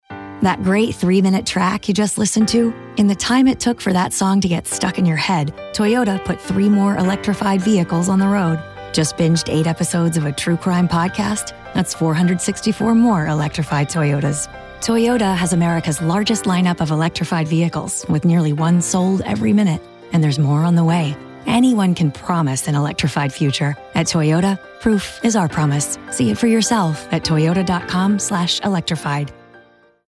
Seasoned voiceover actor with a broad range of skills
Toyota Radio Ad
Toyota Electrified Radio 30.mp3